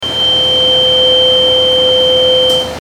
Tuning fork 6
Category: Sound FX   Right: Personal